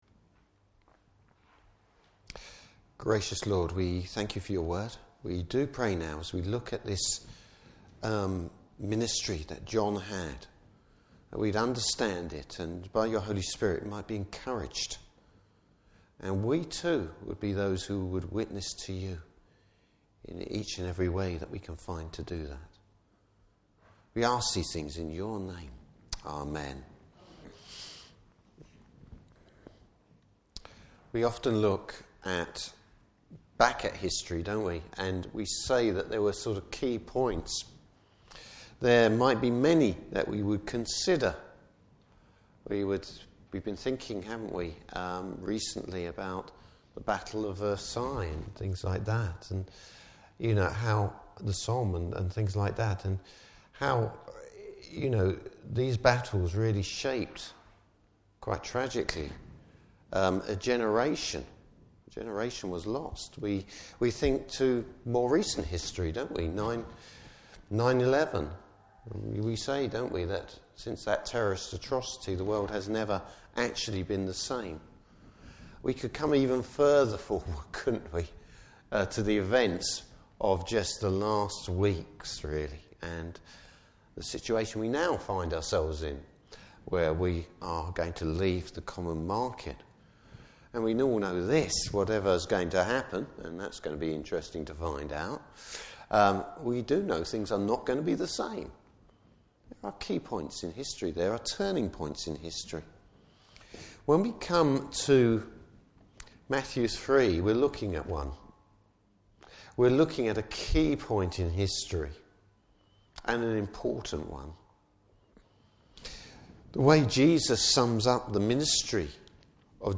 Service Type: Morning Service Bible Text: Matthew 3.